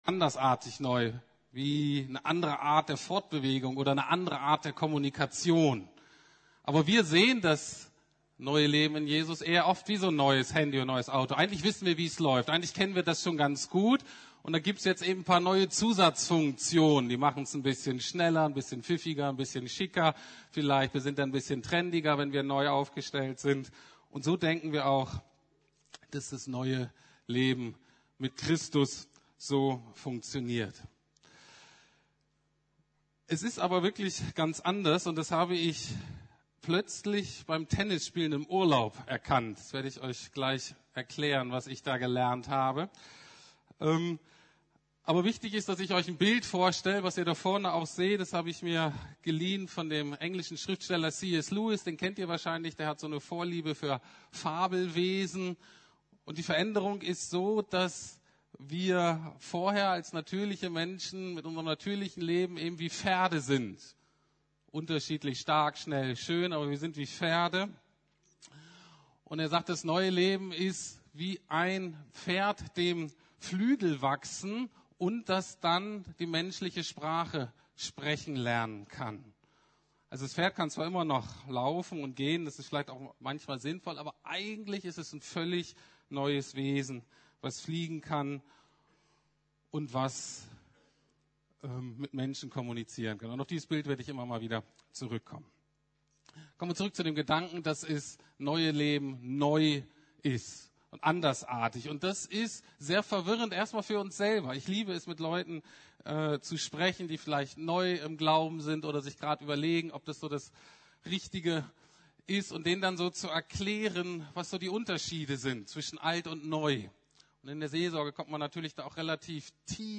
Neues Leben in Christus ~ Predigten der LUKAS GEMEINDE Podcast